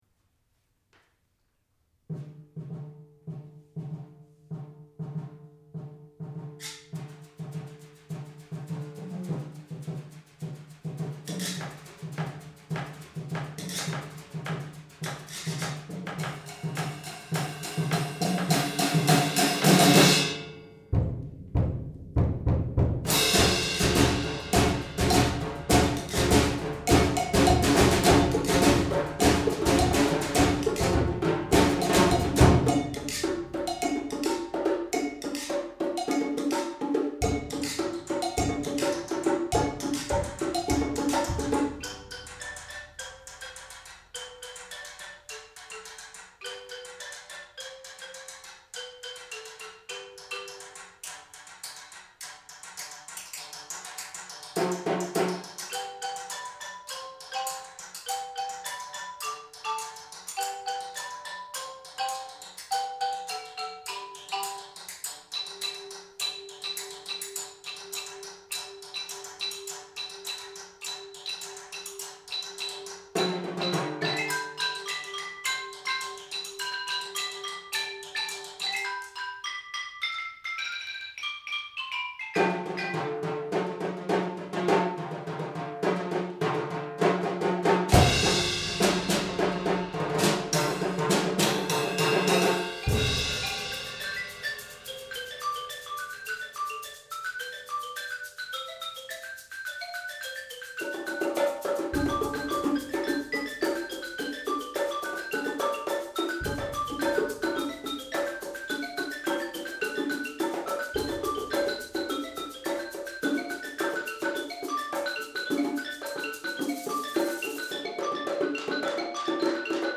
Genre: Percussion Ensemble
# of Players: 5
Player 1 (Snare Drum, Xylophone*)
Player 2 (Guiro, Cowbell, Xylophone*)
Player 3 (Timbales)
Player 4 (Suspended Cymbal, Bongos, Xylophone*)
Player 5 (Bass Drum, Egg Shaker, Xylophone*)